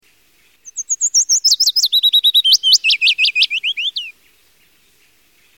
Pouillot fitis
Phylloscopus trochilus
Son chant différent et plus mélodieux est surtout la bonne manière de le différencier.